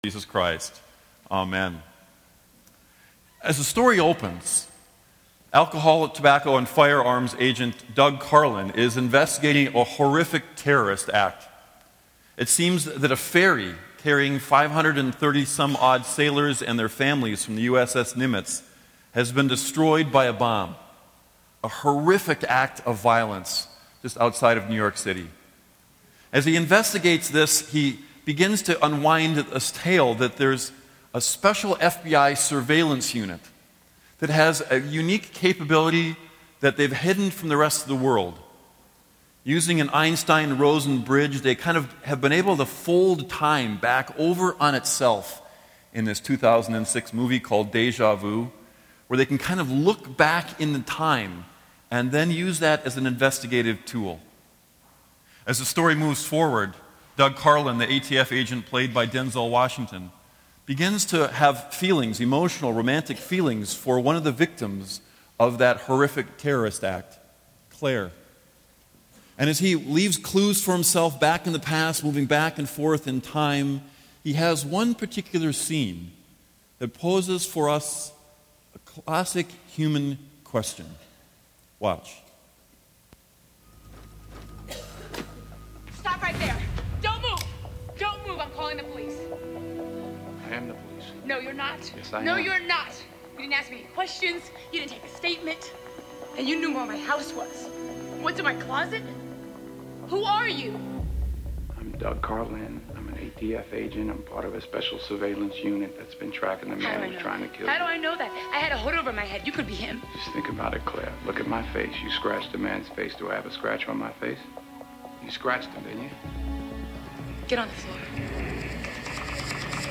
Doubt Sermon